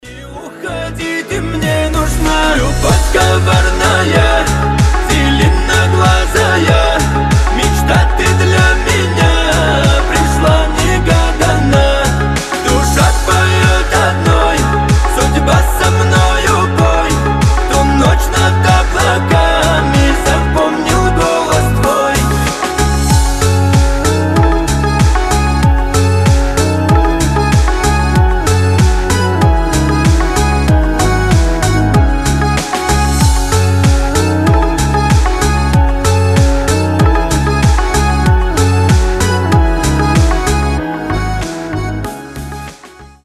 мужской голос